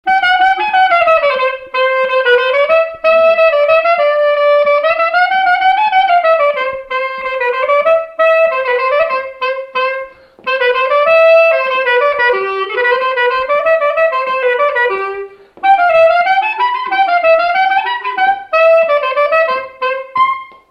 instrumental
danse : quadrille : pas d'été
Pièce musicale inédite